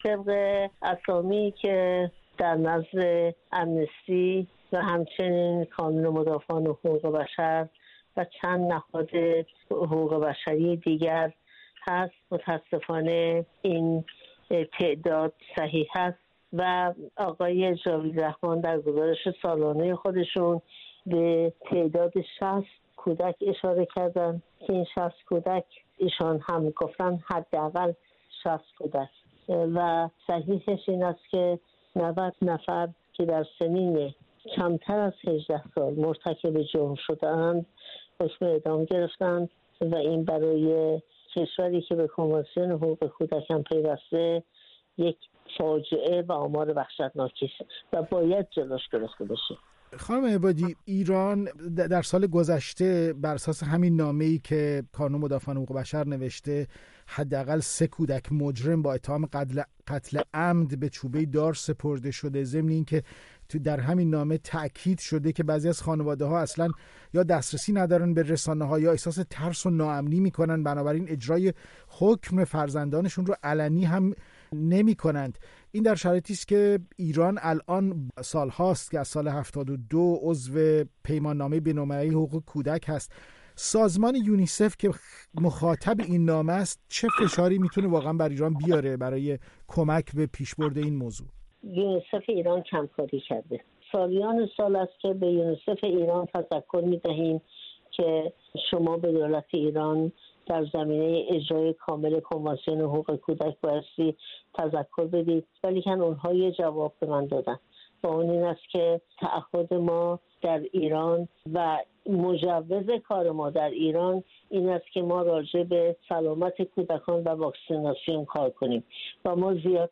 در گفتگو با "شیرین عبادی" حقوقدان و از بنیانگذاران کانون مدافعان حقوق بشر، ابتدا درباره این کودکانِ در انتظارِ اعدام پرسیده است!